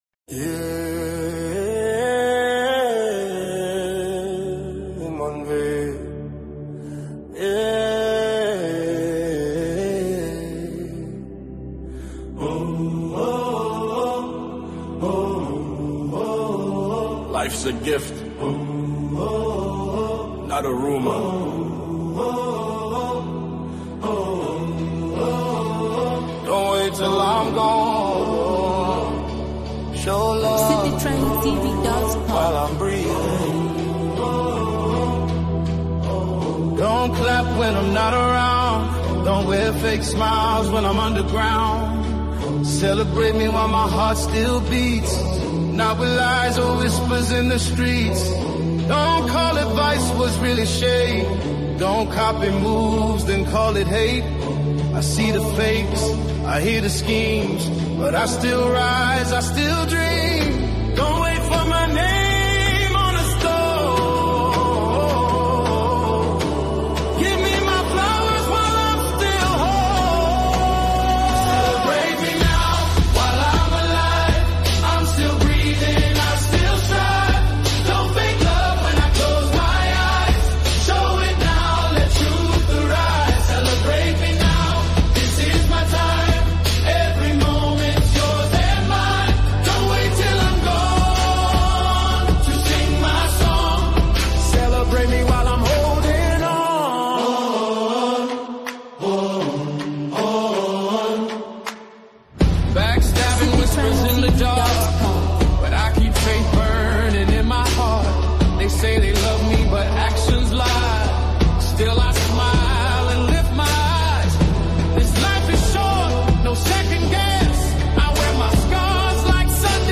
resonant and emotionally honest